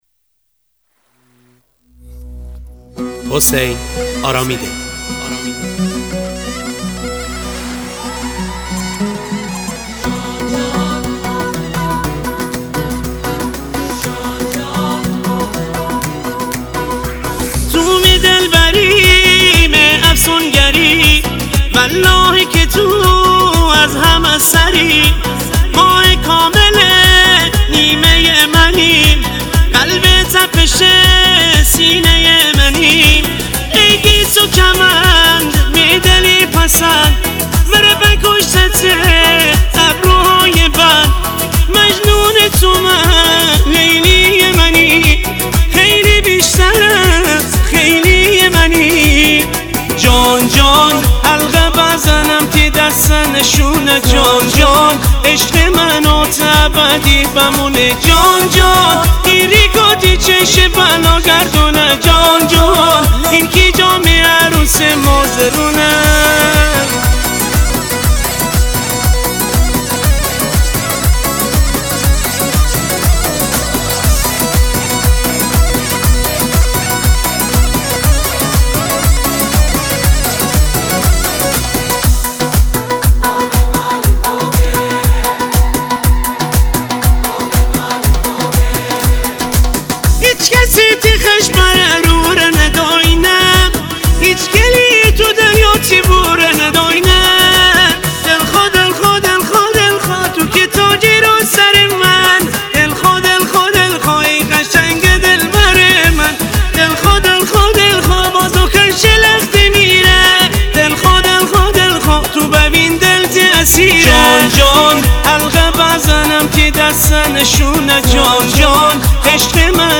ریتمیک ( تکدست )
شاد